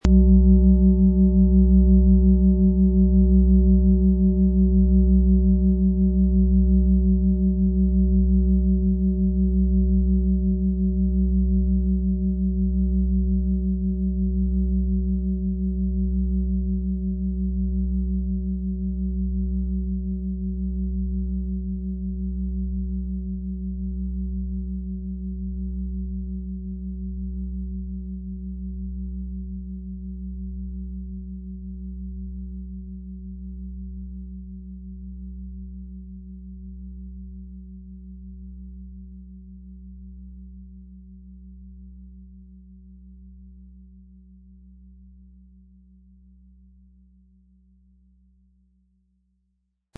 Die XXL Sonne-Mond-Fußklangschale verbindet das Strahlen der Sonne mit der sanften Tiefe des Mondes.
PlanetentonSonne
MaterialBronze